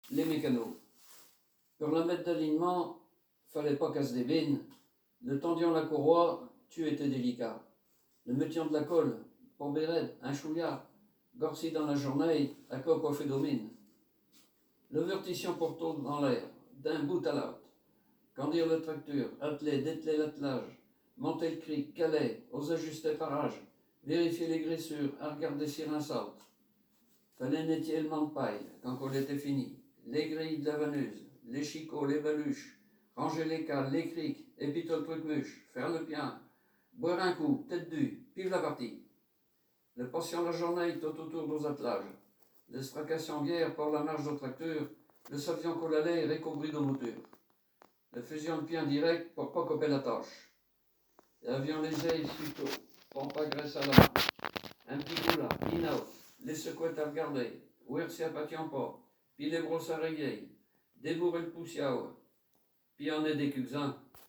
Genre poésie